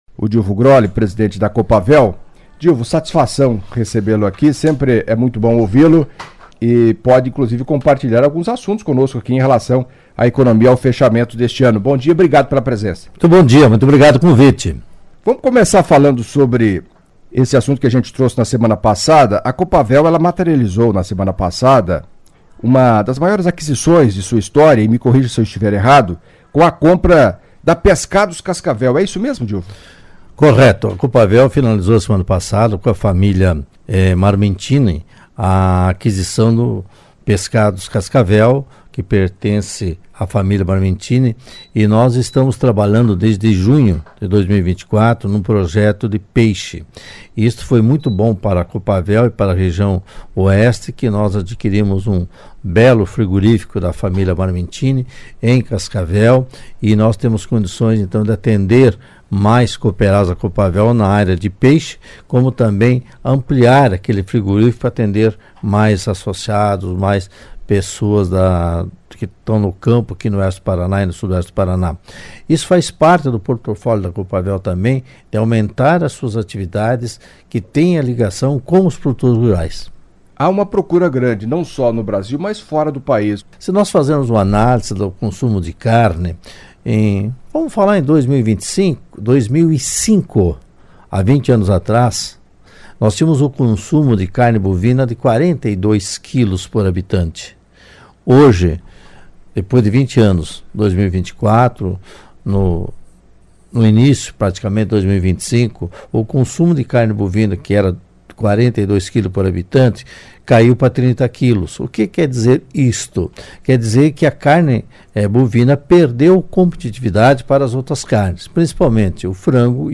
Em entrevista à CBN nesta quinta-feira (26)